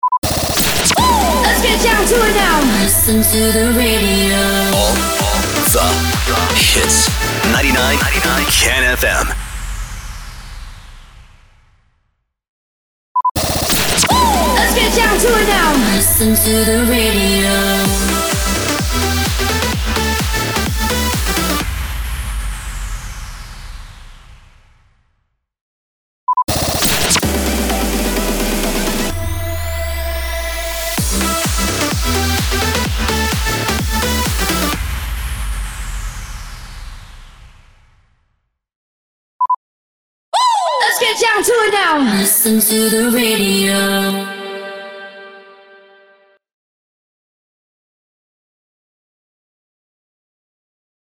609 – SWEEPER – LISTEN TO THE RADIO